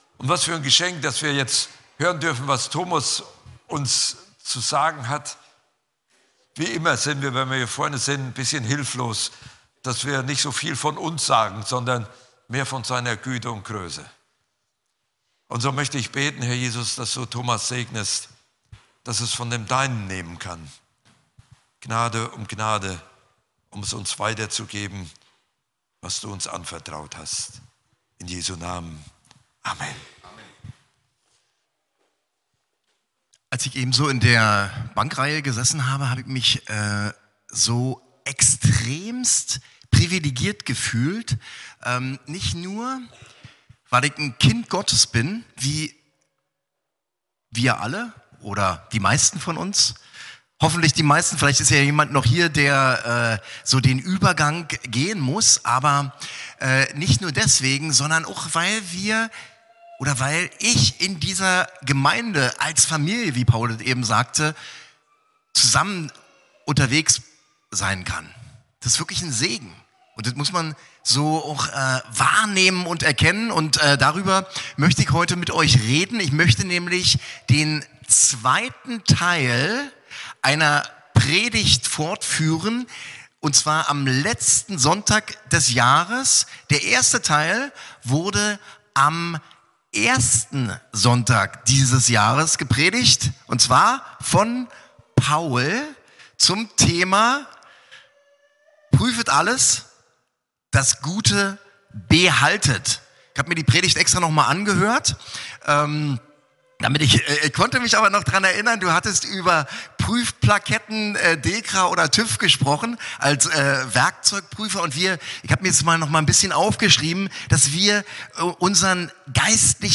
Gottesdienst vom 28.12.2025